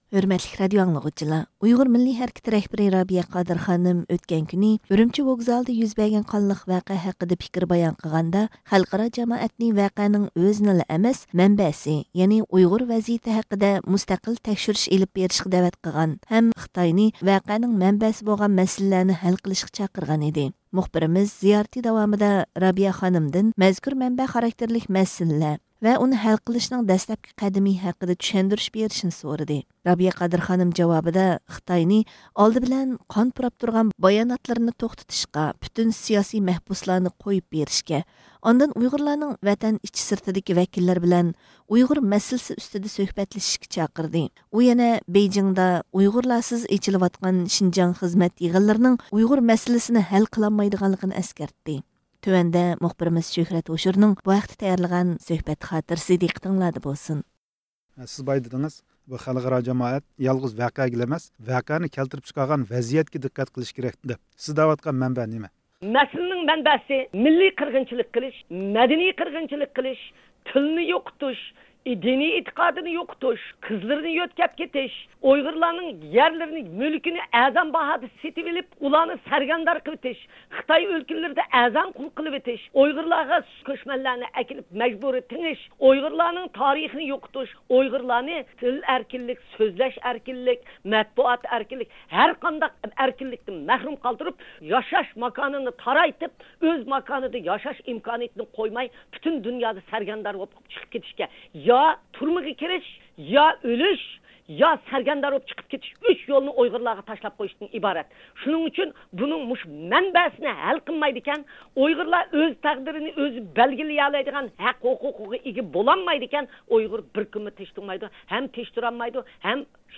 مۇخبىرىمىز زىيارىتى داۋامىدا رابىيە خانىمدىن مەزكۇر مەنبە خاراكتېرلىك مەسىلىلەر ۋە ئۇنى ھەل قىلىشنىڭ دەسلەپكى قەدىمى ھەققىدە چۈشەندۈرۈش بېرىشىنى سورىدى.